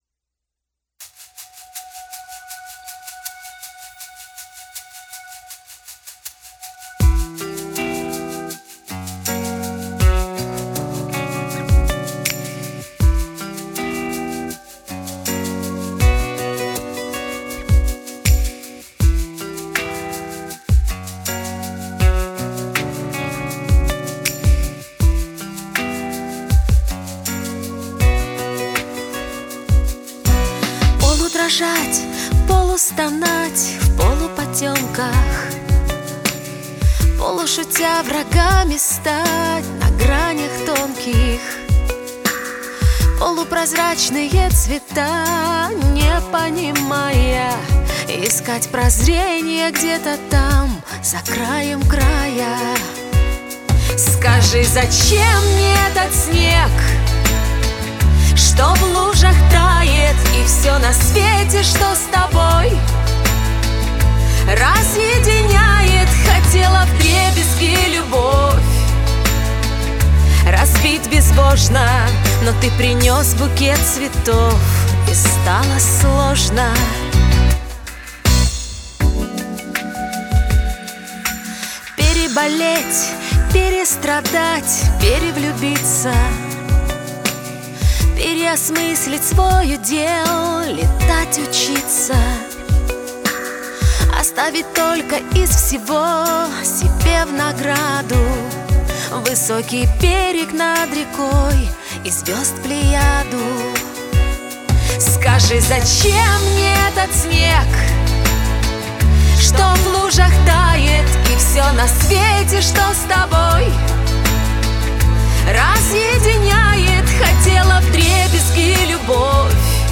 Мнения по работе (поп)
Чтобы не быть голословным, взял для примера первый микс, и проделал вышесказанное.
НМВ низ чуть подсобрался, как и микс в общем.